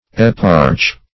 Eparch \Ep"arch\, n. [Gr.